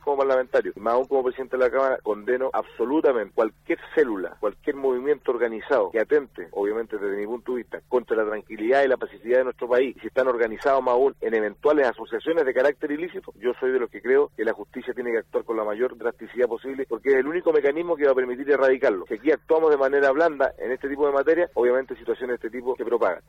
En entrevista con radio Sago el Diputado Fidel Espinoza compartió su opinión como parlamentario de la nación sobre la situación que se vive actualmente en Chile y que guarda directa relación con los último acontecimientos de quema de camiones, maquinarias y templos religiosos, así como la huelga de hambre que realizaron comuneros mapuches detenidos por su participación en dichos hechos.